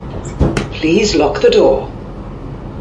instructing toilet users to "Please lock the door" on an LNER Azuma. Recorded on-board. 1